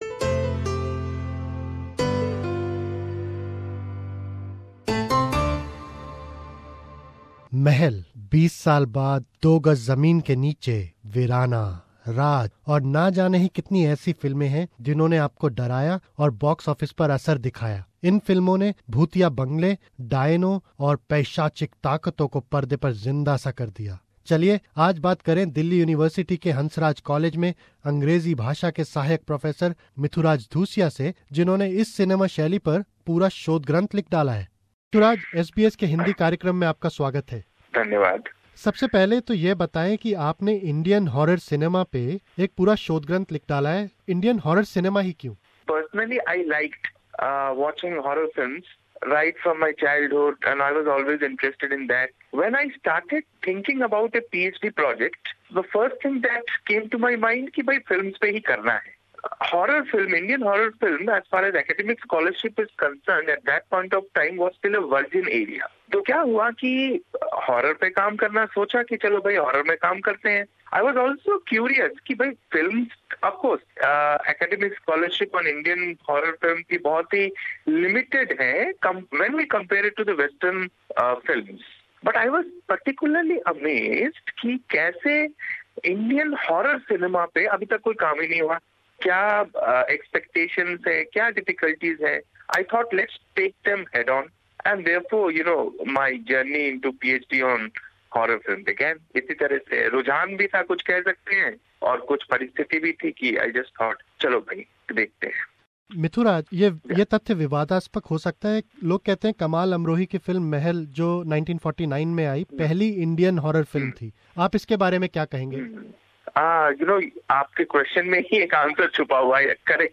These timeless movies have explored the themes of haunted houses, evil spirits and demonic forces. We talk to Assistant Professor